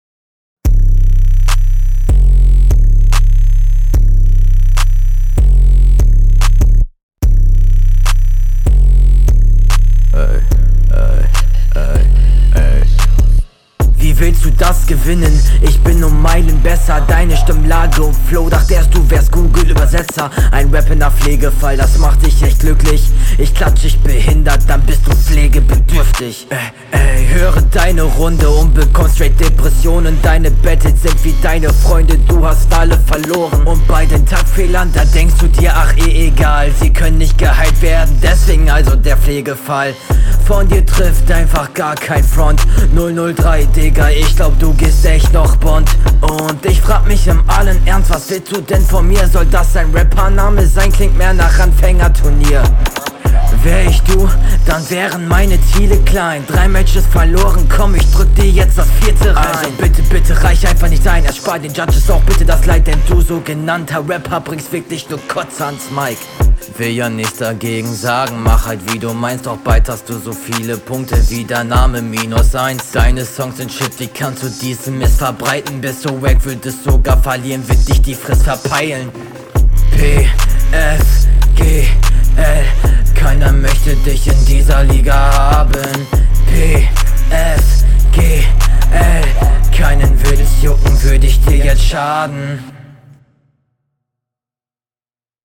Flow: Der Ansatz und die Variationen im Flow gefallen mir, sitzen aber nicht 100%ig auf …
ich mag beats mit ner 808 die bumm macht :) stimmeneinsatz echt cool ist alles …